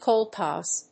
/kɑlkˈɔːz(米国英語), kɑlkˈɔːs(英国英語)/